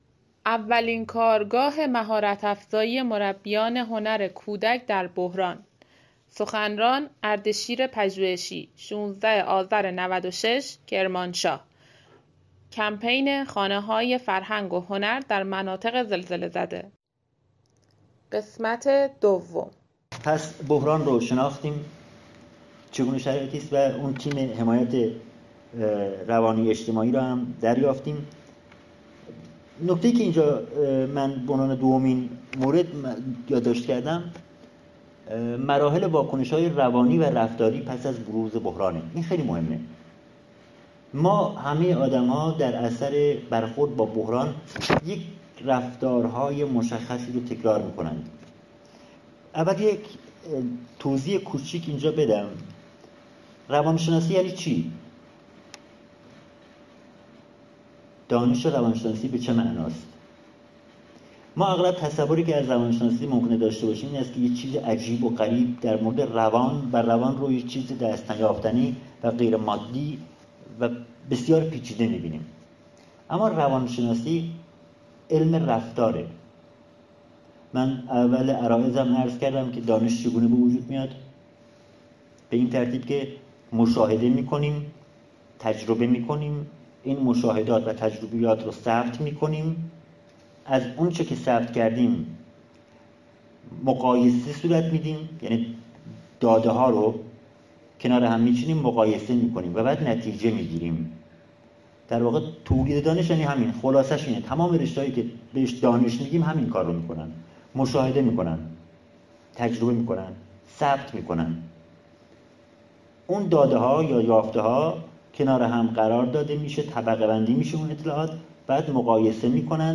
فایل صوتی ارزشمند اولین کارگاه مهارت افزایی مربیان هنرکودک در بحران